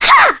Ophelia-Vox_Attack2.wav